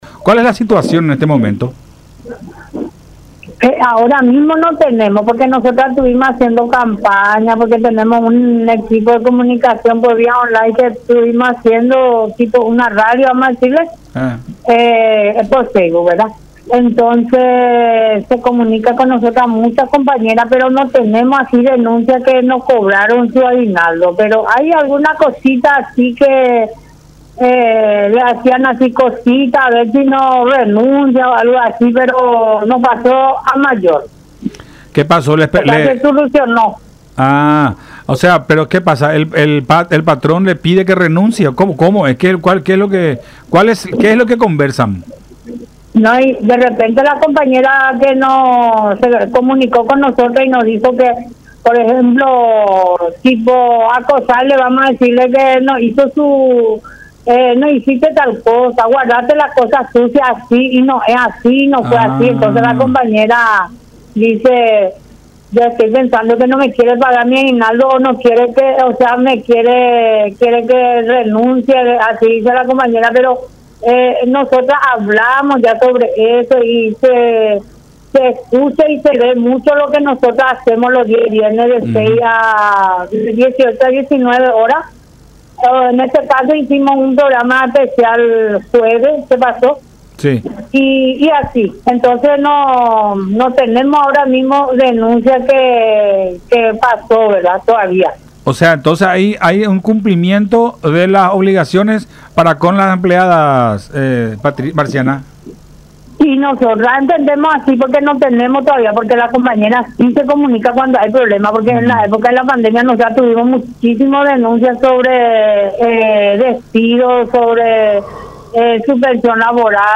en charla con Buenas Tardes La Unión.